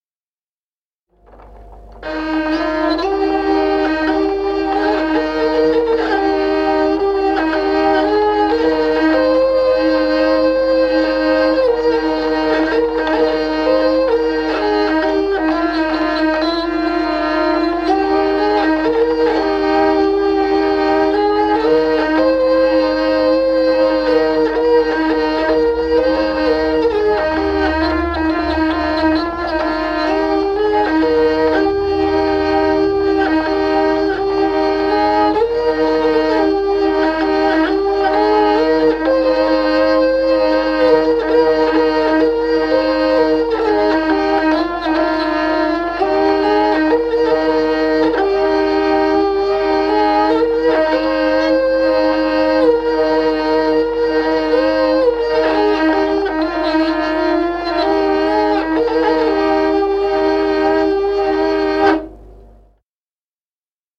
Музыкальный фольклор села Мишковка «Бедные птички», партия лиры.